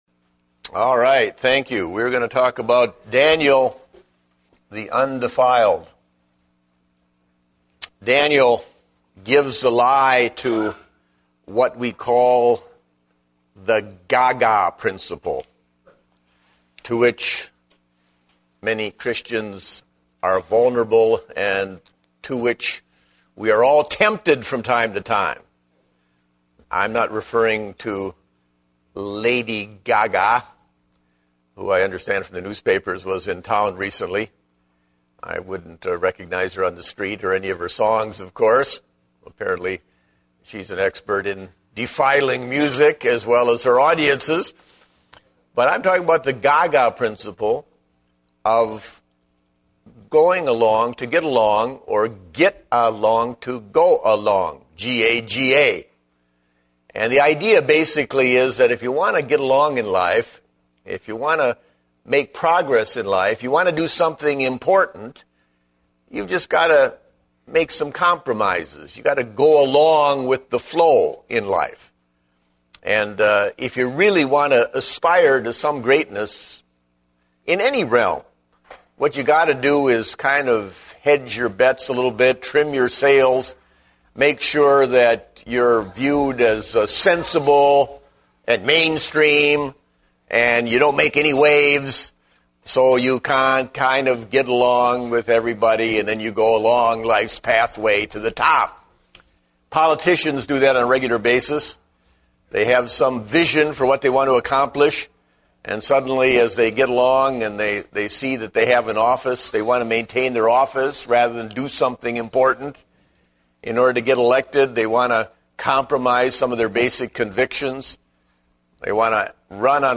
Date: September 5, 2010 (Adult Sunday School)